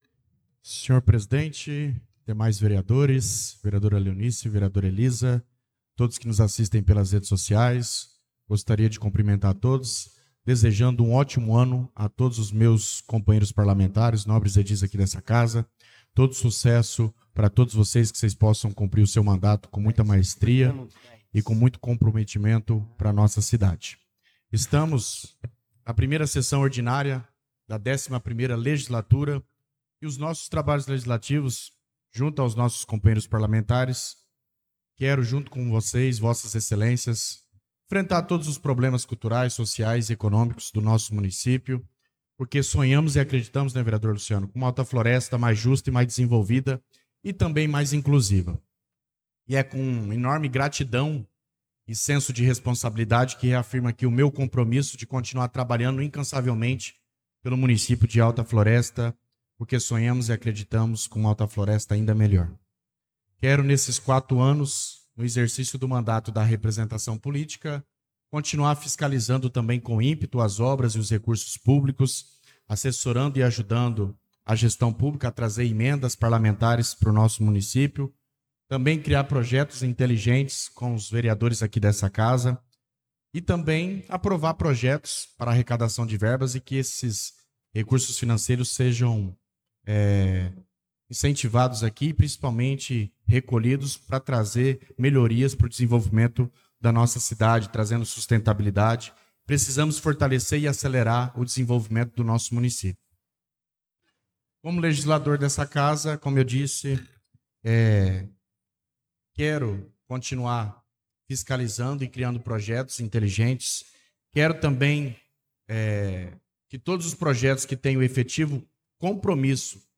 Pronunciamento do vereador Douglas Teixeira na Sessão Ordinária do dia 04/02/2025